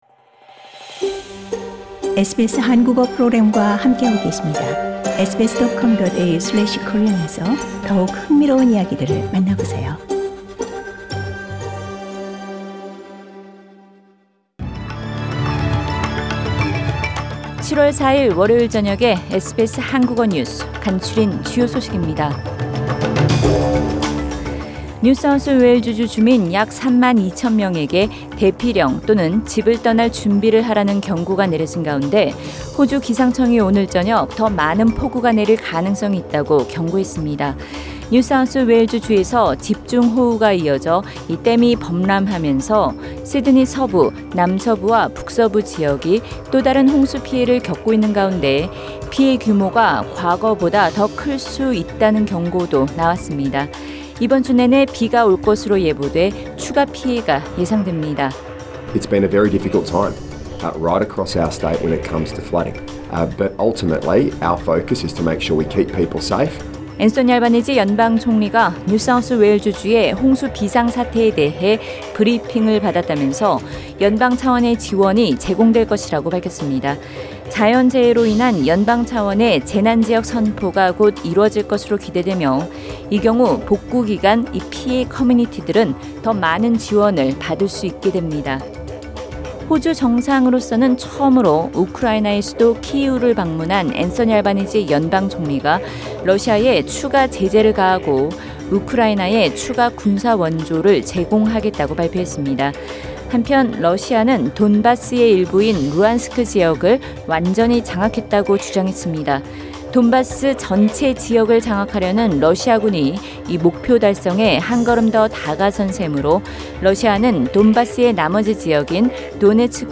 SBS 한국어 저녁 뉴스: 2022년 7월 4일 월요일